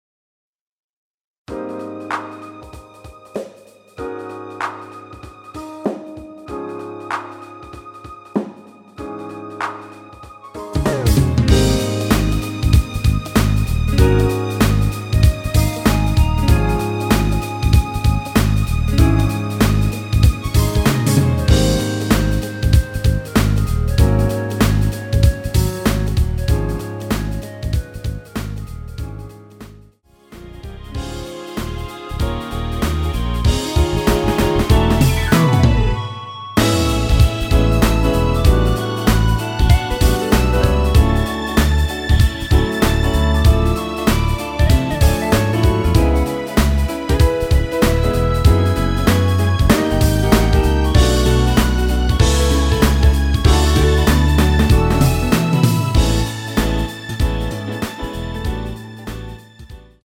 원키 멜로디 포함된 MR입니다.
Ab
앞부분30초, 뒷부분30초씩 편집해서 올려 드리고 있습니다.